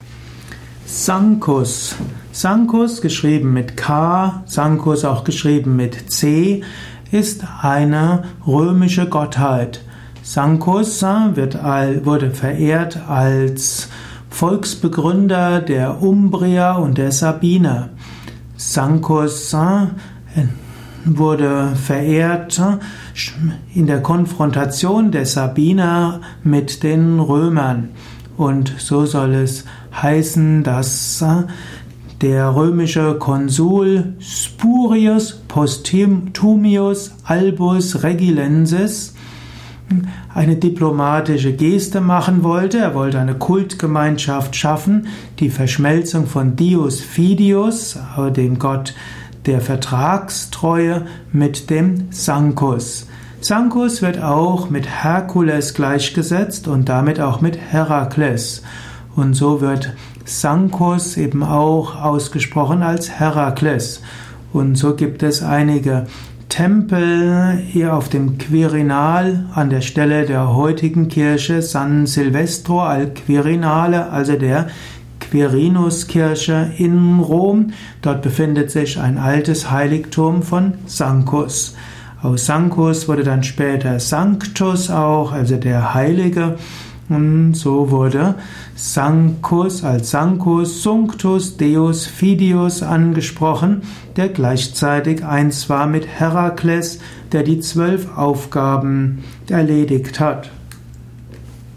Ein Vortrag über Sankus, einem italischen Gott.
Welche Bedeutung hat Gott Sankus für das spirituelle Leben, die Persönlichkeitsentwicklung, die spirituelle Praxis, die religiöse Philosophie? Dies ist die Tonspur eines Videos, zu finden im Yoga Wiki.